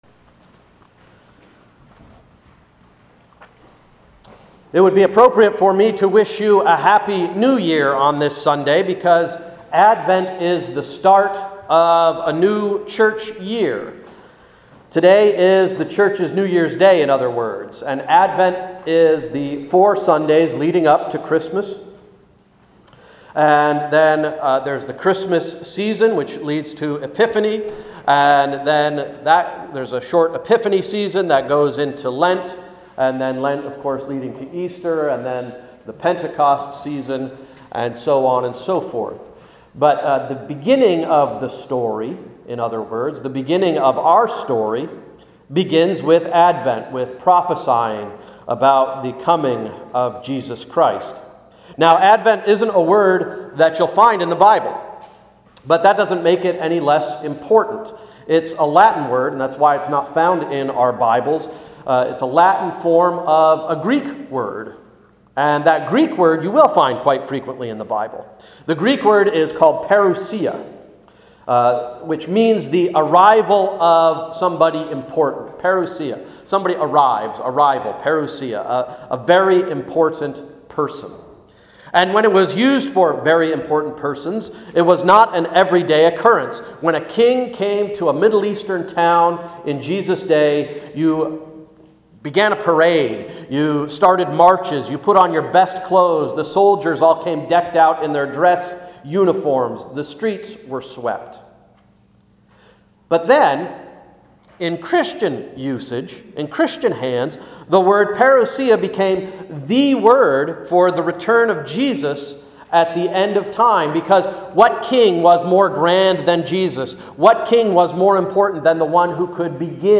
Sermon of November 27, 2011–“Making Mud Pies”